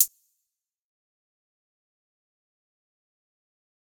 OZ - HH 3.wav